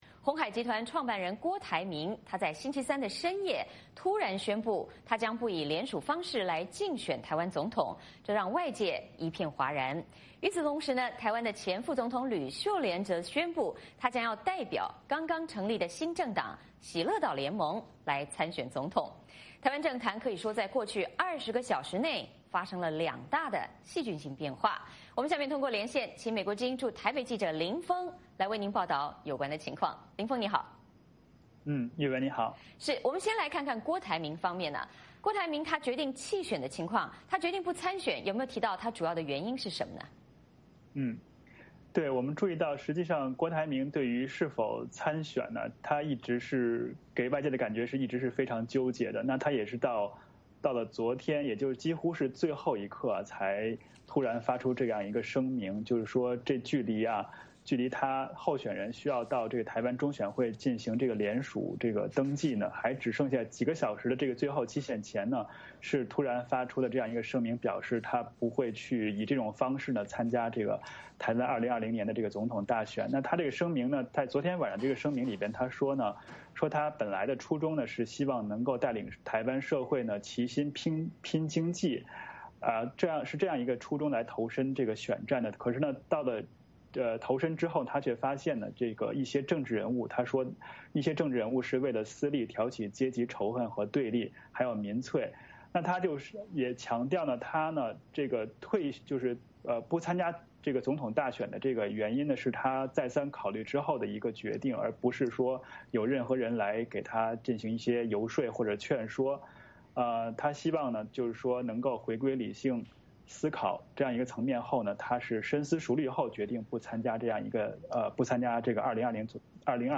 VOA连线